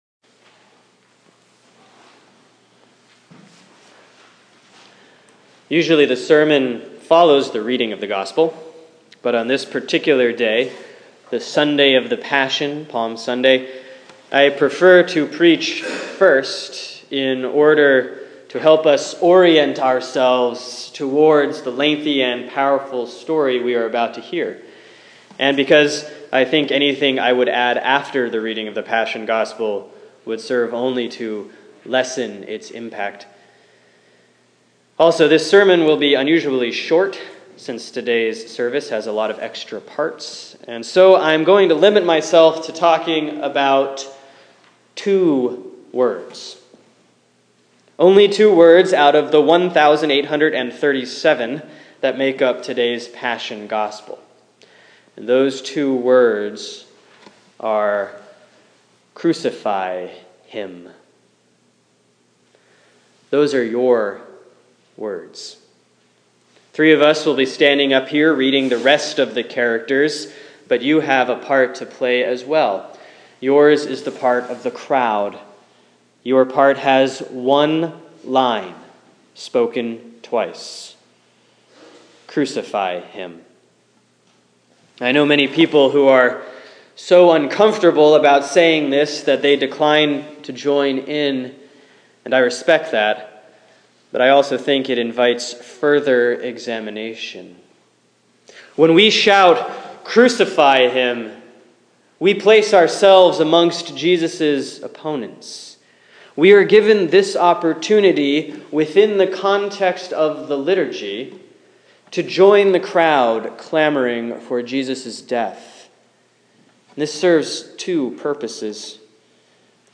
Sermon for March 29, 2015 || The Sunday of the Passion: Palm Sunday Year B || Mark 14:32 – 15:47